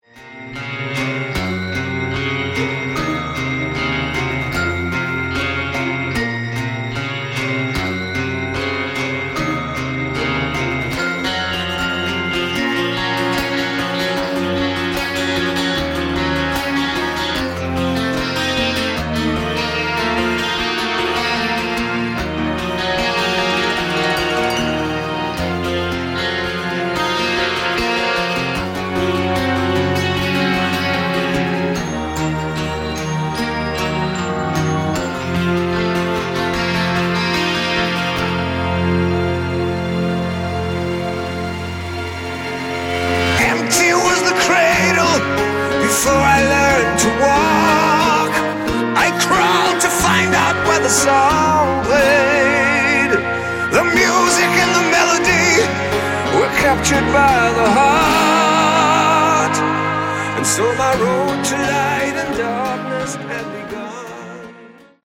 Category: Melodic Metal
vocals
drums
bass
guitars